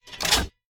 select-cannon-3.ogg